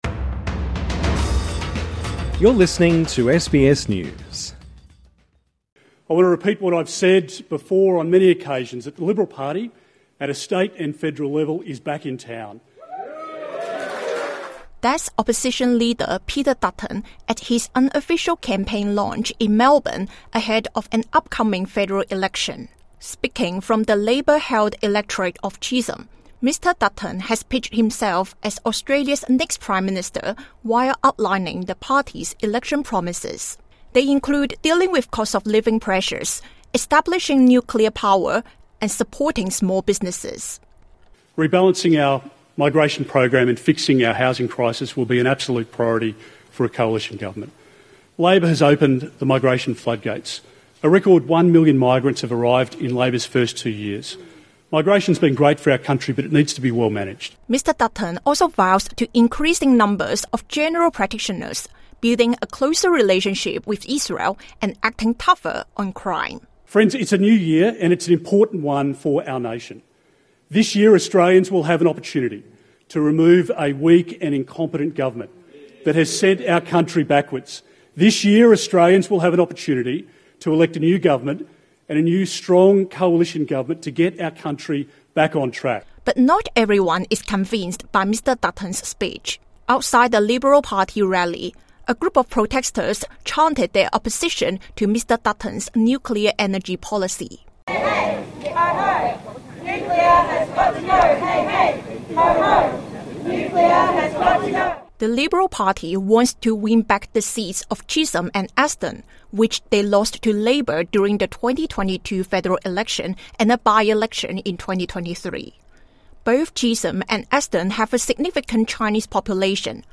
Leader of the Opposition Peter Dutton has unofficially launched his election campaign at a rally in Melbourne.